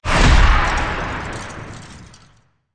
playerdies_2.ogg